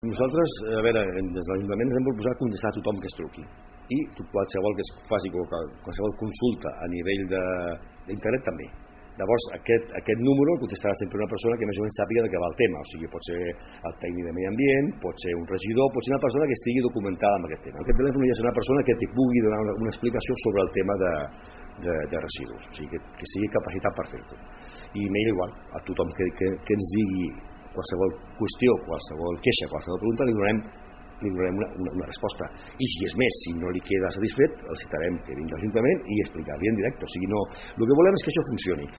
Martí Victòria, regidor de Serveis del consistori, explica que tothom que contacti per comunicar incidències o bé fer algun tipus de queixa o suggeriment tindrà resposta de l’Ajuntament.